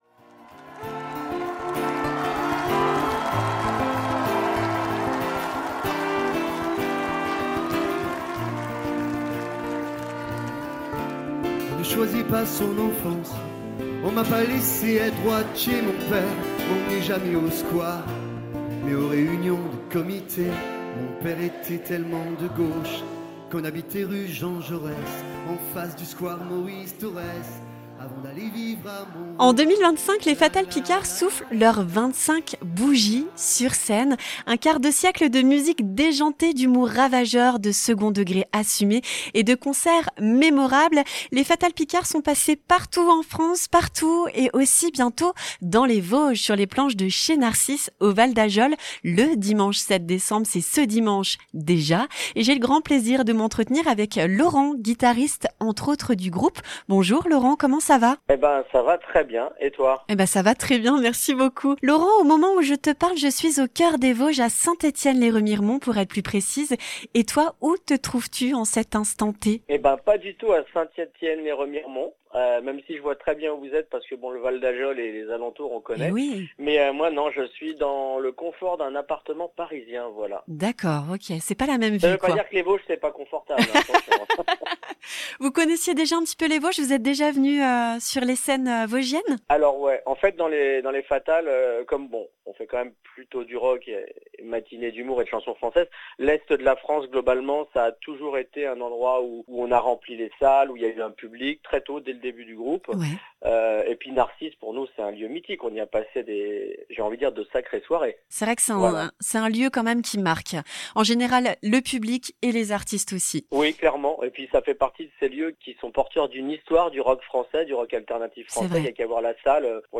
Interview : Les fatals picards fêtent leurs 25 ans de carrière et en profitent pour passer Chez Narcisse !